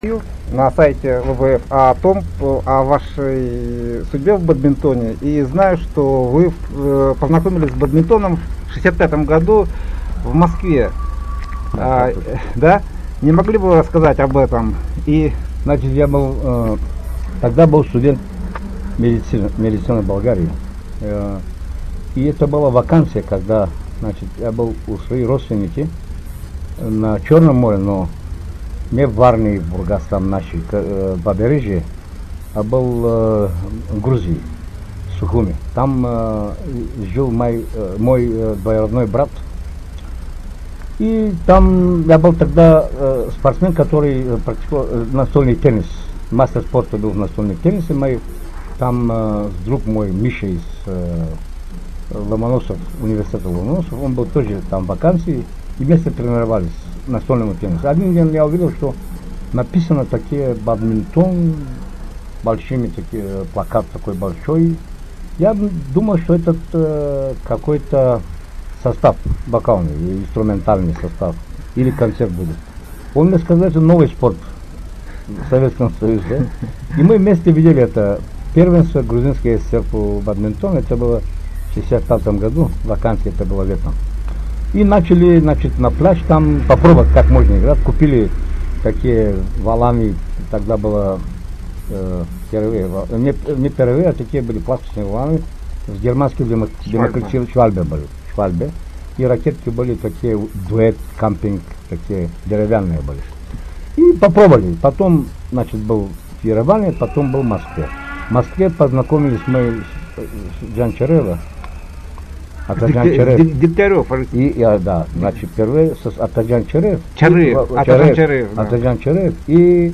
София.
24-28 июня в столице Болгарии в Софии проходит XXXII Кубок Европы среди клубных команд-чемпионов.